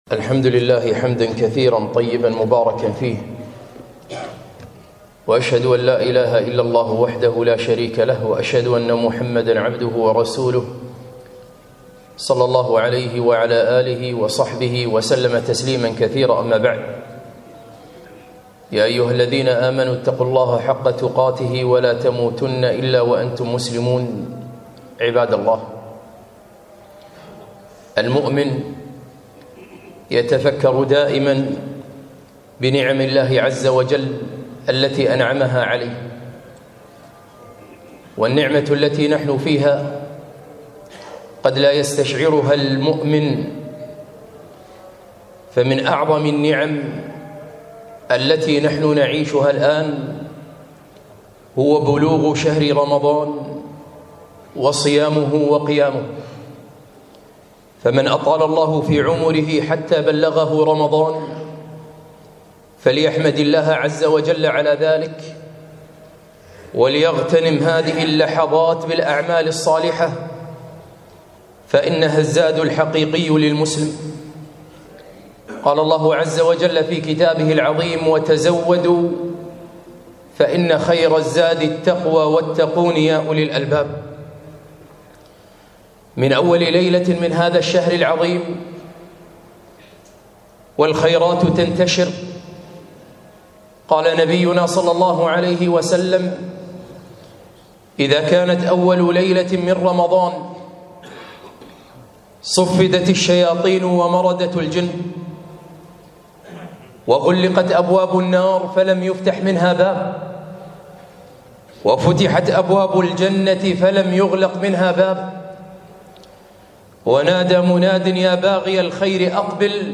خطبة - شهر الخيرات